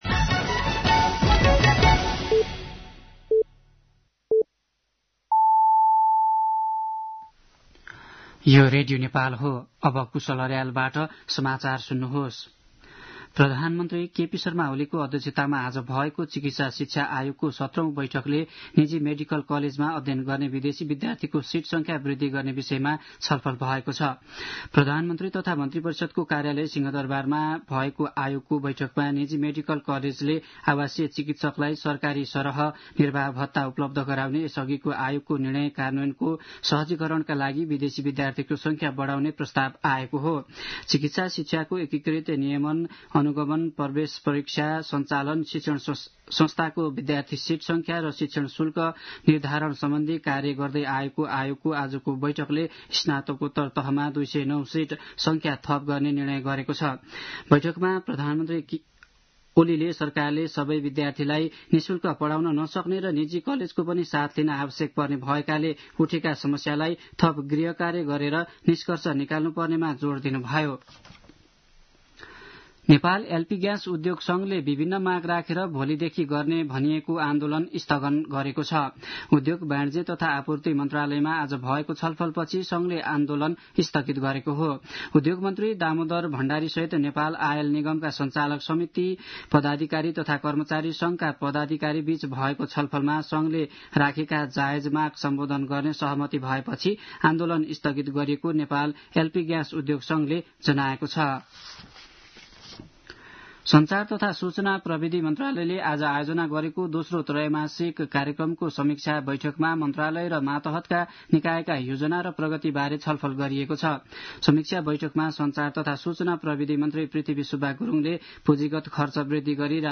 साँझ ५ बजेको नेपाली समाचार : १ फागुन , २०८१
5-pm-nepali-news-10-30.mp3